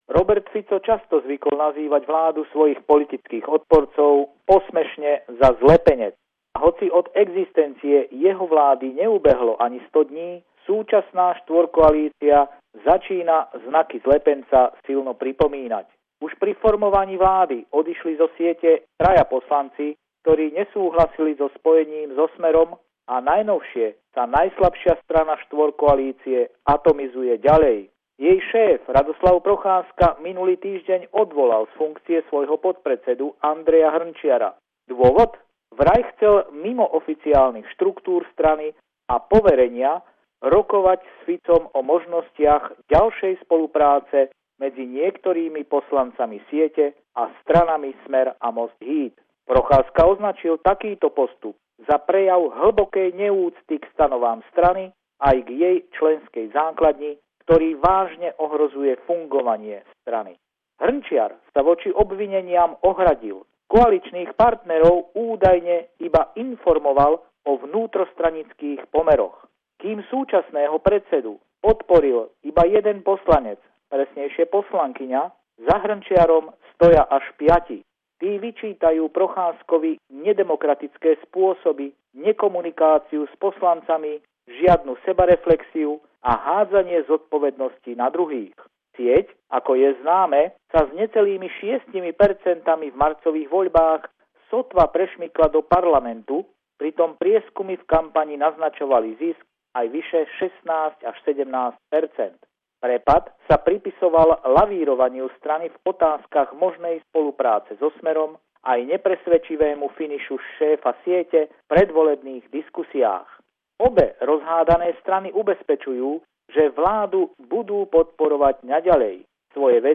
Pravidelný telefonát týždňa z Bratislavy